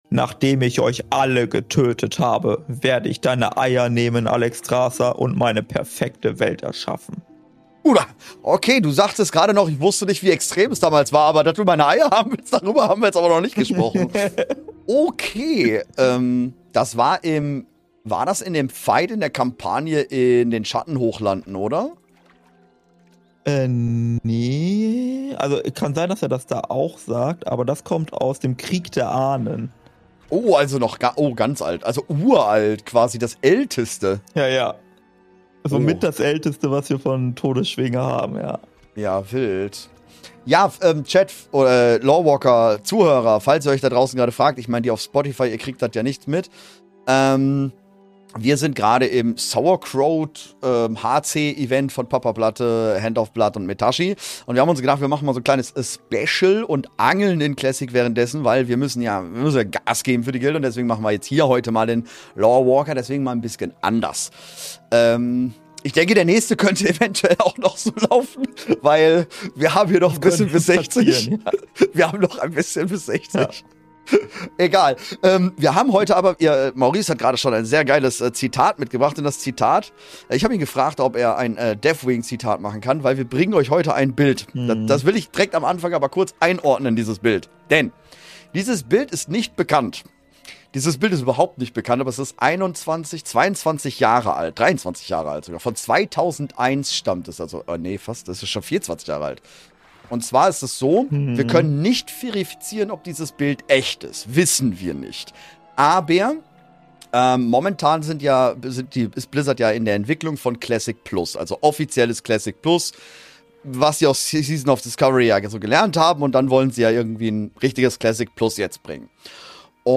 Die Atmosphäre ist entschleunigt, nostalgisch und ganz nah an den Ursprüngen von World of Warcraft.